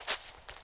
StepForest3.ogg